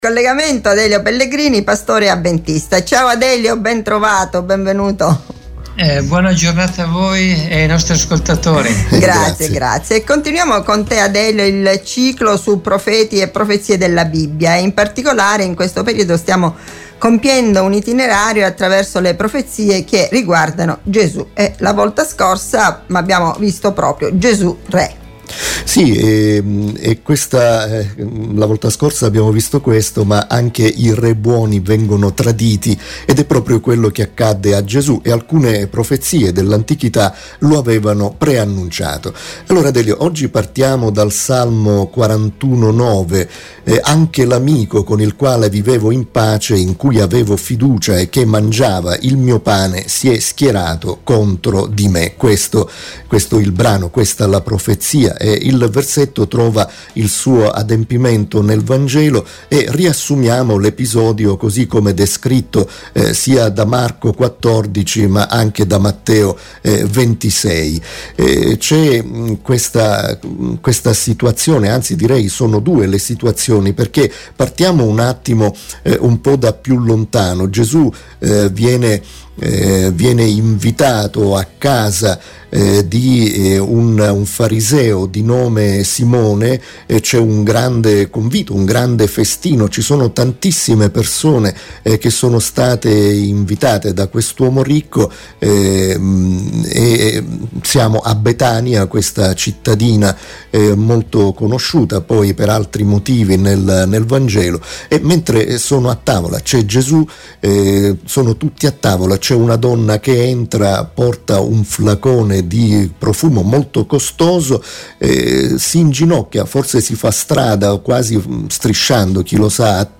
intervistano